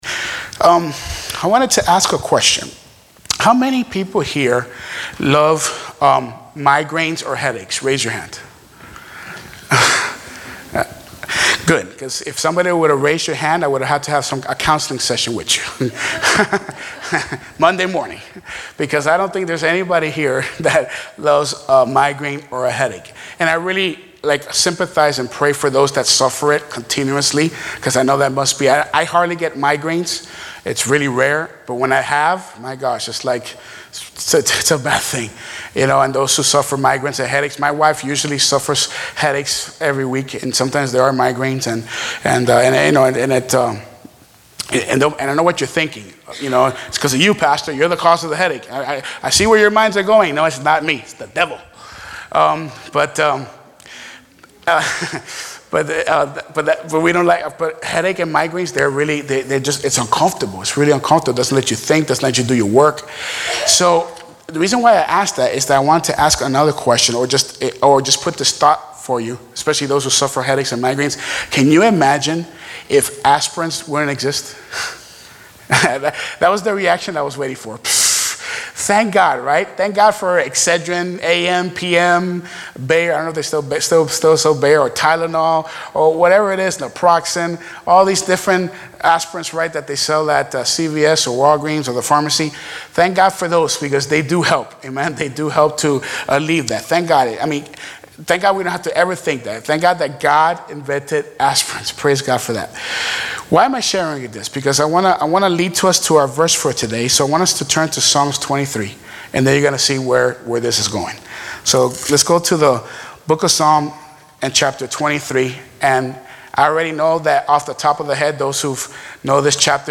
Sermons - Buena Vista Baptist